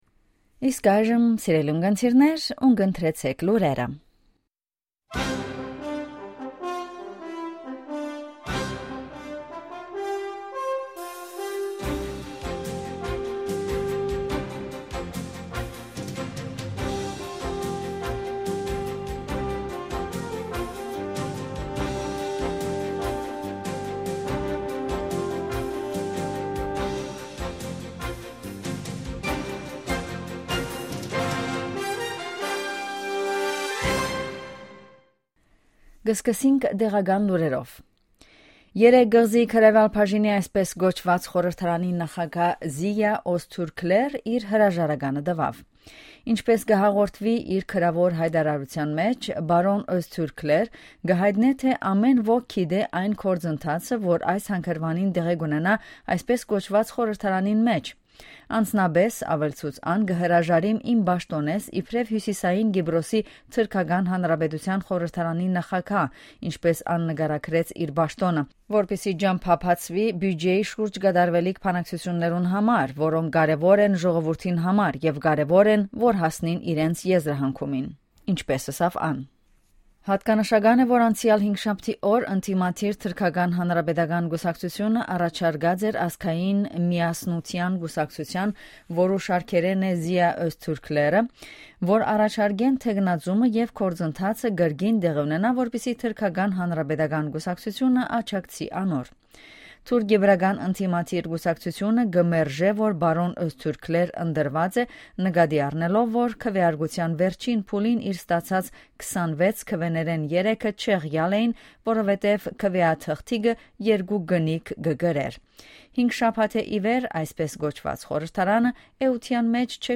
Ειδήσεις στα Αρμένικα - News in Armenian
Καθημερινές Ειδήσεις στα Αρμένικα. Daily News in Armenian.